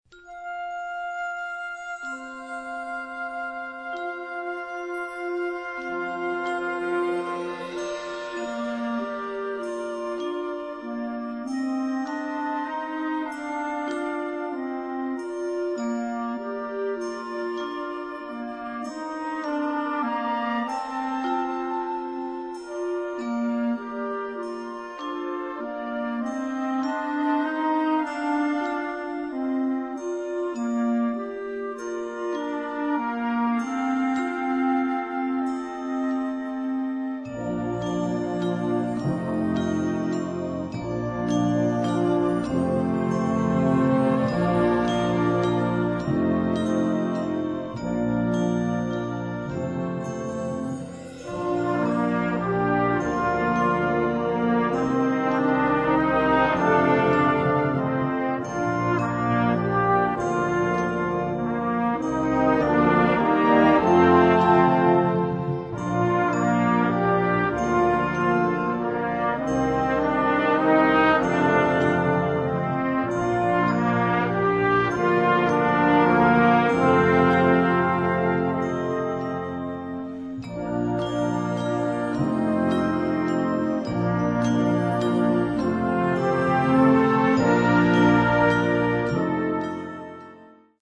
This simple, yet elegant melody
Partitions pour orchestre d'harmonie des jeunes.